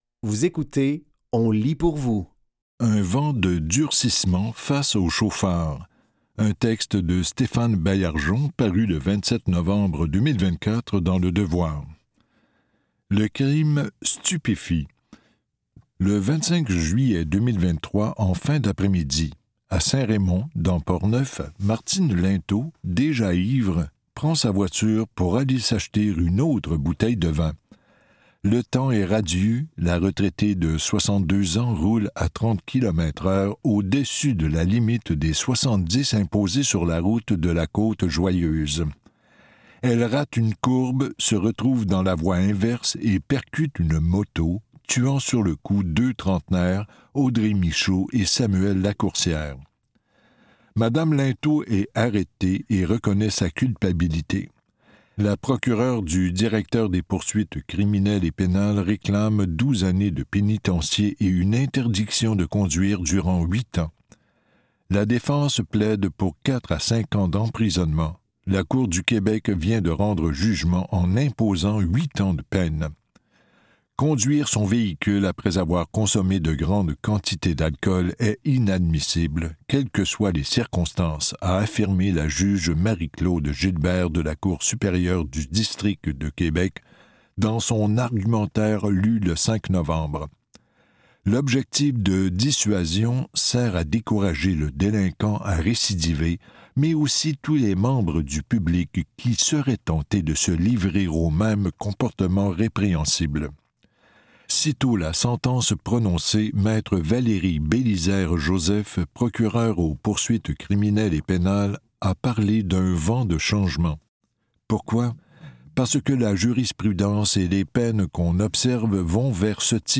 Dans cet épisode de On lit pour vous, nous vous offrons une sélection de textes tirés des médias suivants : Le Devoir et Québec Science.